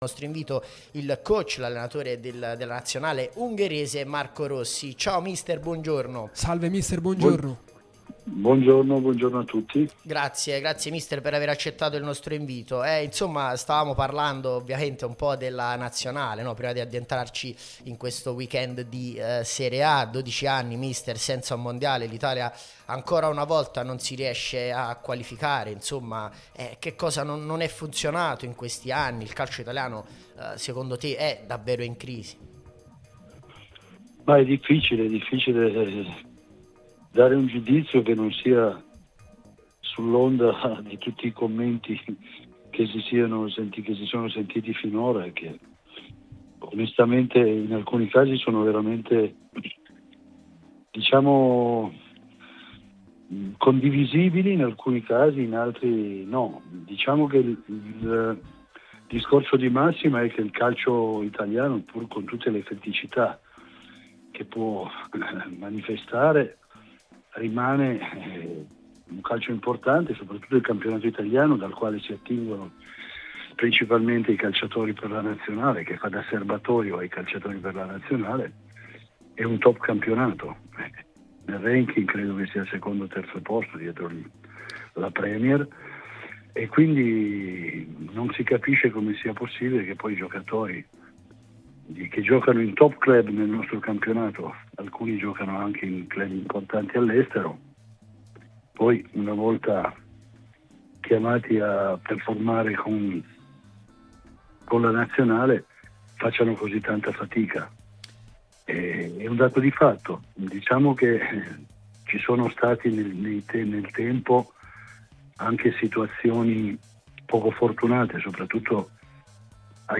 Marco Rossi a Radio Tutto Napoli: la crisi dell’Italia tra moduli e risultatismo, Spalletti e il valore della Serie A. Il futuro con l'Ungheria